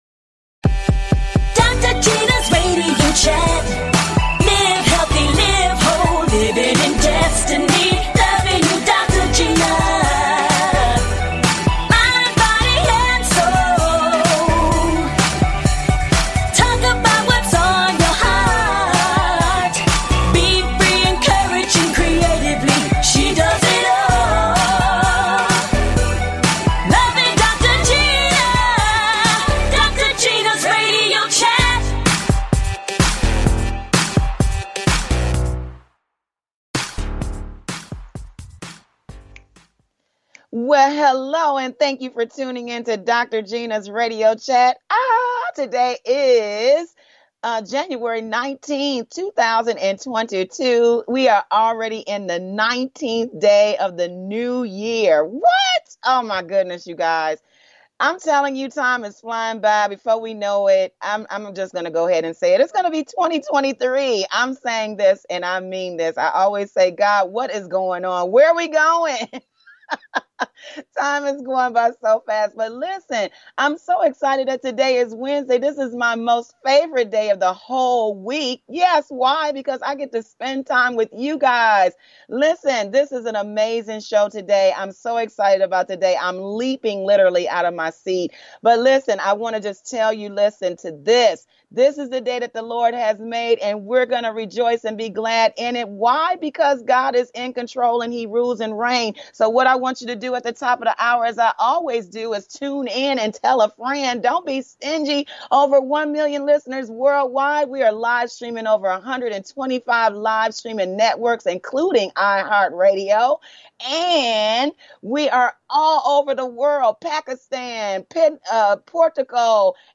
Talk Show Episode, Audio Podcast
A talk show of encouragement.